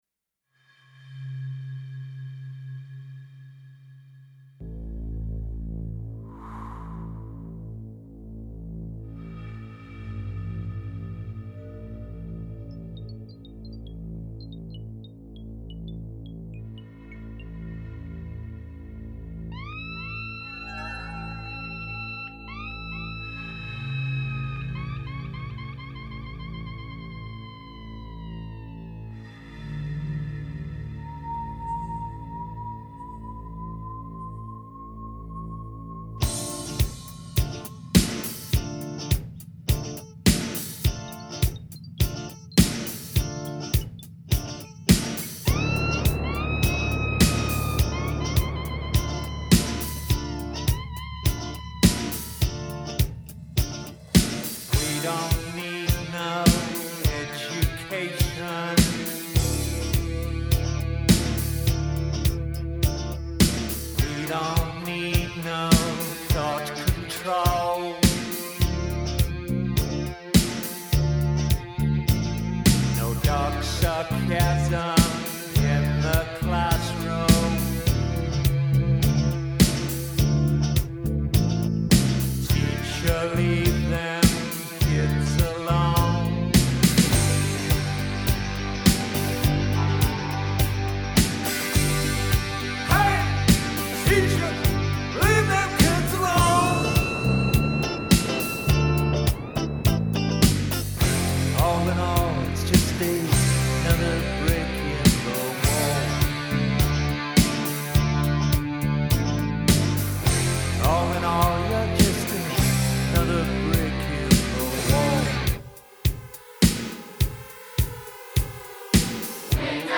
new studio recording